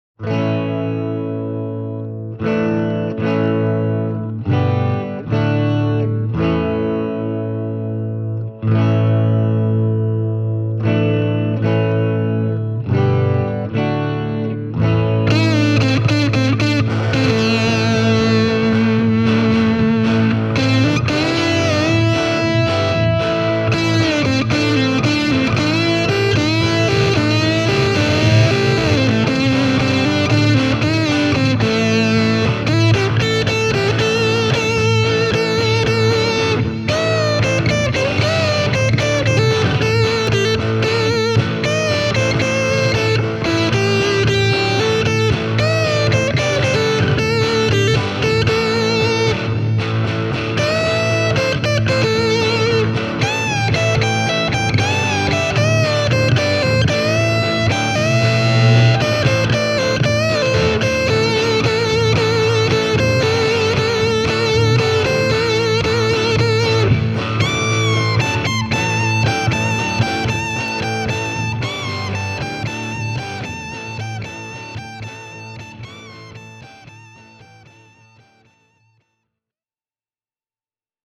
Demobiisissä käytin sekä Juketone True Blood -vahvistinta (Tweed Champ -klooni) että Bluetone Shadows Jr. -komboa. Biisi on äänitetty ilmain efektipedaaleja: